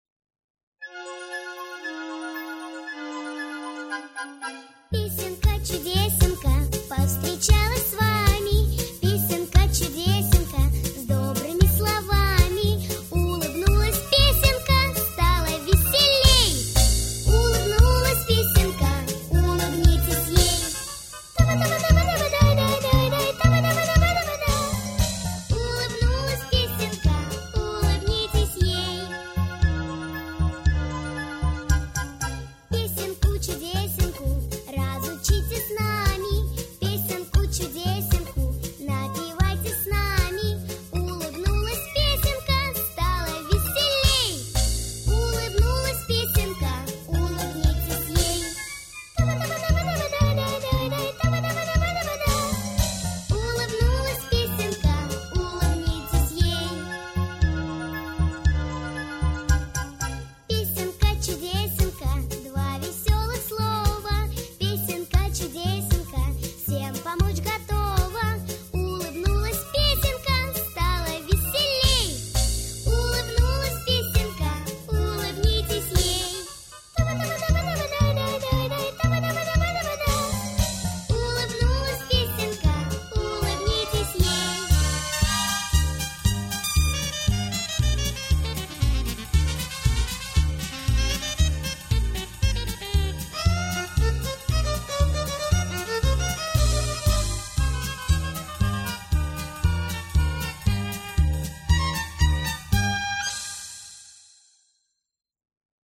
Детская музыка